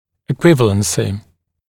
[ɪ’kwɪvələnsɪ] [и’куивэлэнси] эквивалентность